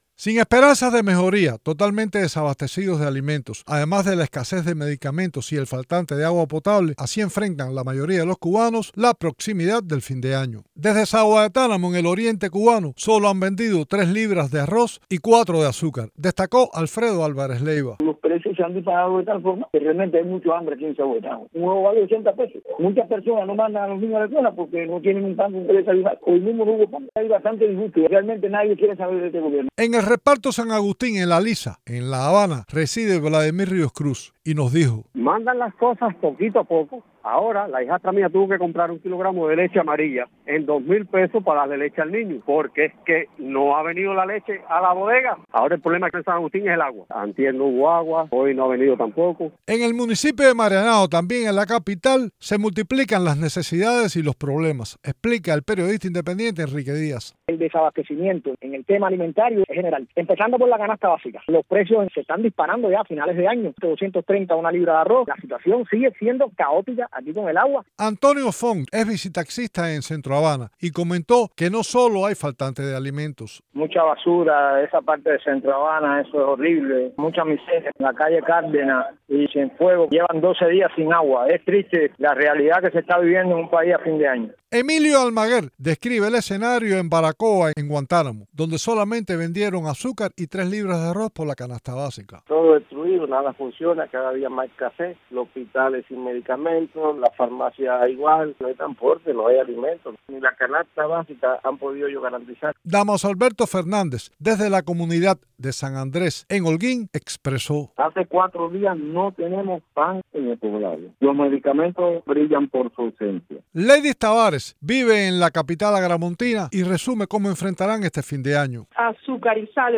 Martí Noticias entrevistó a cubanos en varias provincias del territorio nacional que describieron un panorama sumamente difícil.
Testimonios de cubanos sobre la escasez de alimentos